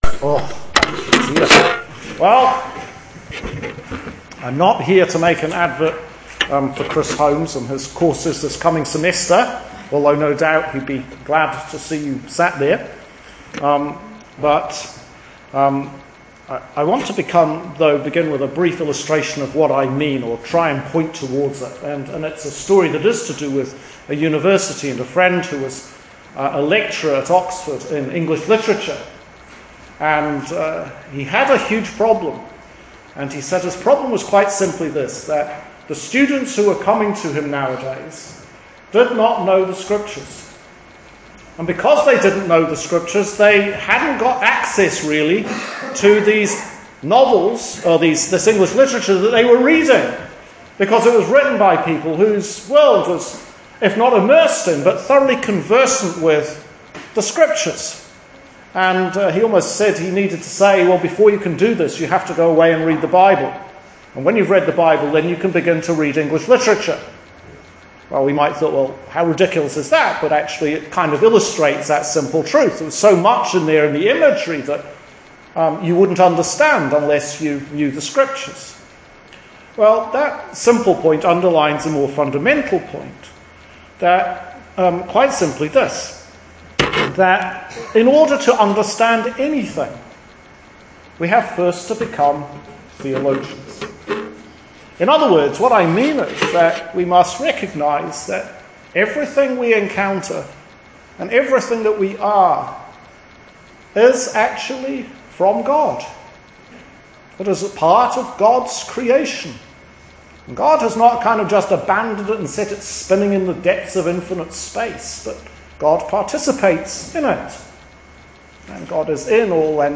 Sermon for 3rd Sunday after the Epiphany – Year C 2019